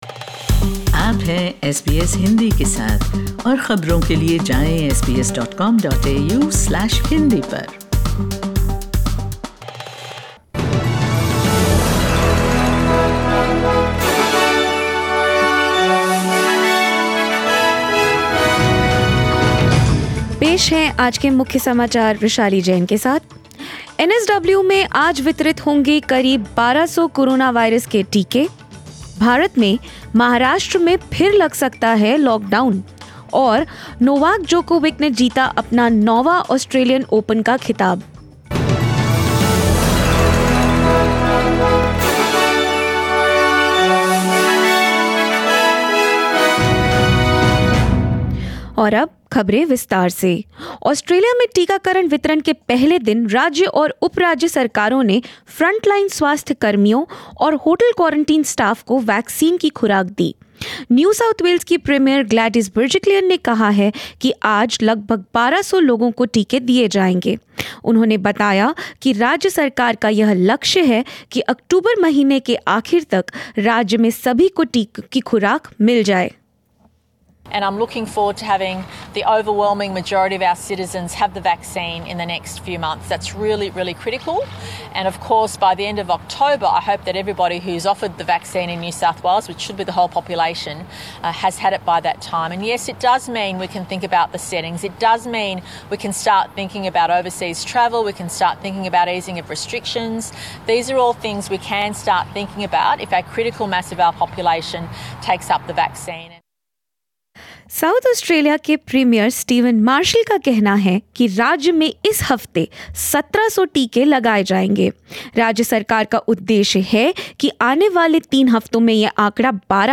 In this bulletin... ** Vaccine rollout begins in Australia...